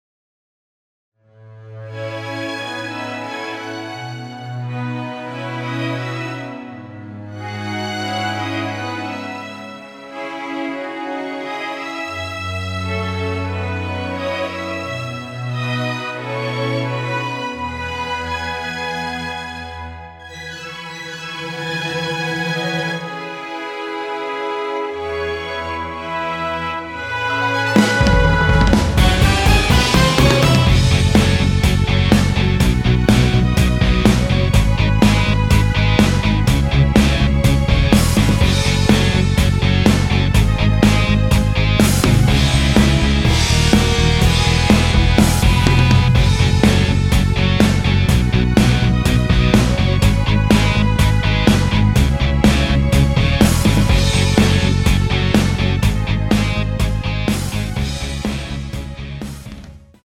원키에서(+5)올린 MR입니다.
C#m
앞부분30초, 뒷부분30초씩 편집해서 올려 드리고 있습니다.